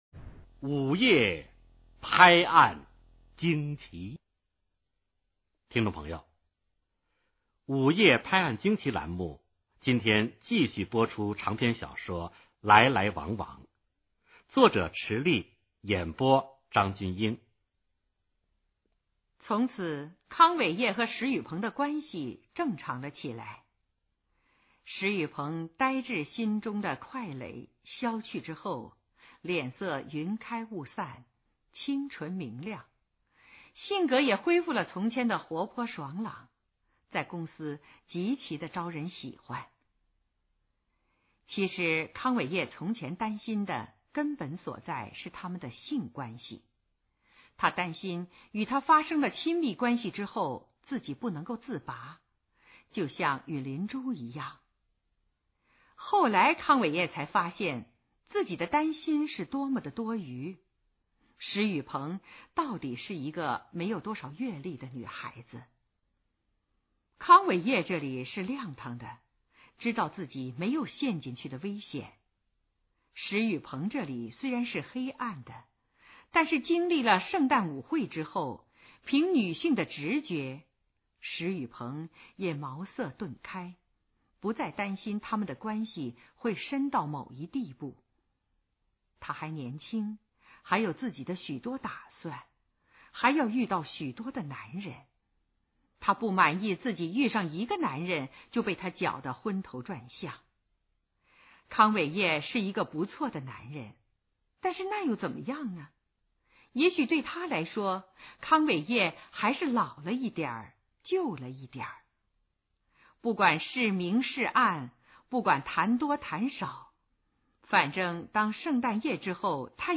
[长篇小说]来来往往(声频版) 作者:池莉;朗读:张筠英